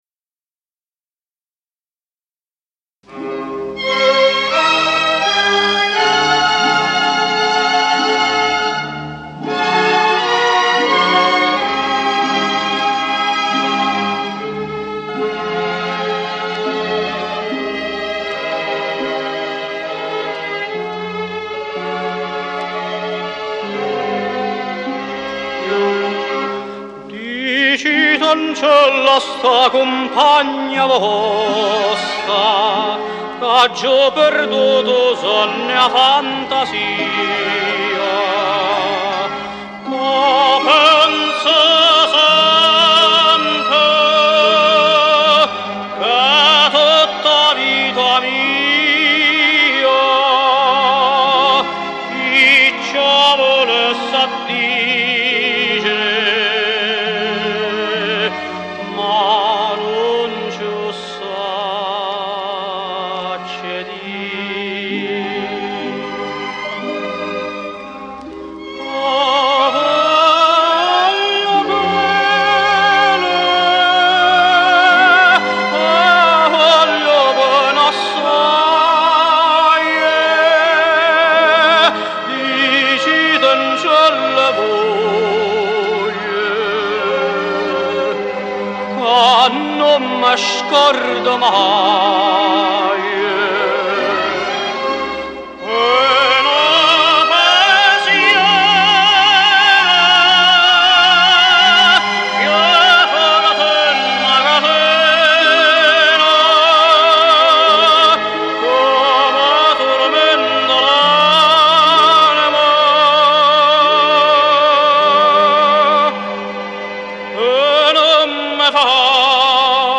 ORCHESTRA
a tenor of extraordinary versatility and tonal quality.
a programme of arias and Neopolitan songs